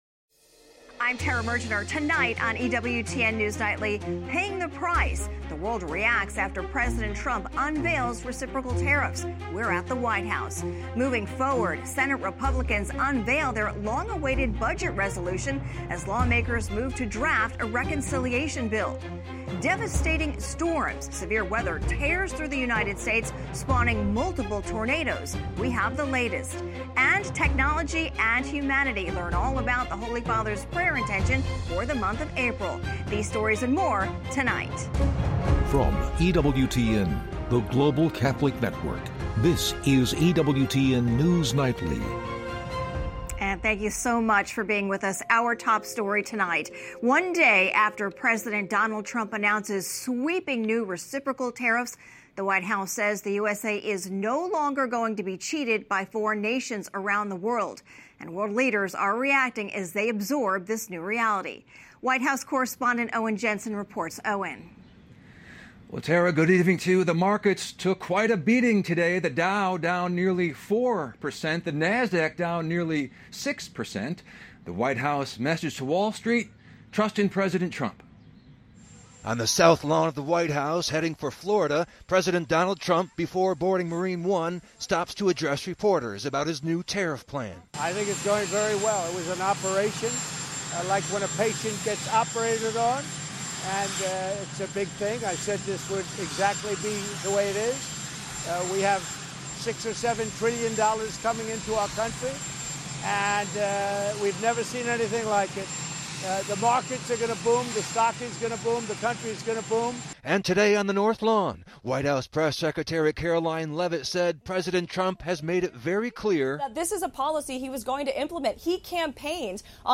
EWTN News Nightly is our daily news and analysis program presenting breaking Catholic News worldwide, top stories, and daily reports from the White House, Capitol Hill, and Rome.